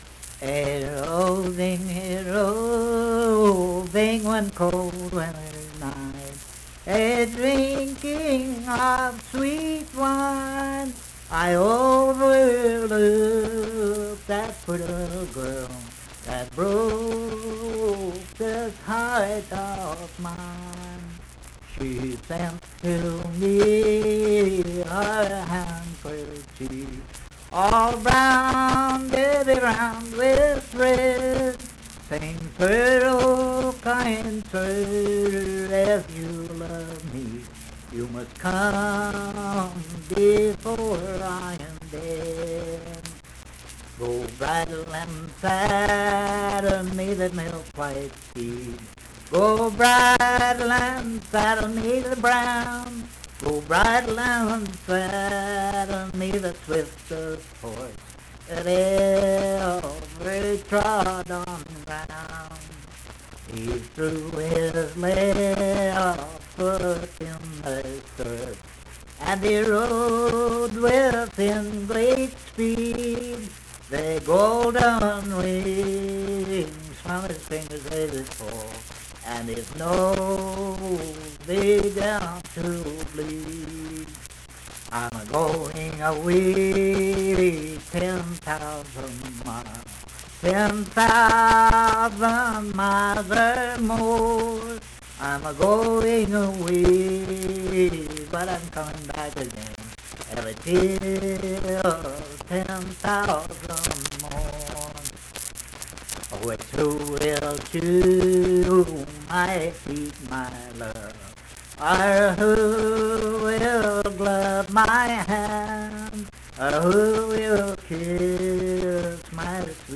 Unaccompanied vocal music
Verse-refrain 11(4).
Voice (sung)
Cabell County (W. Va.), Huntington (W. Va.)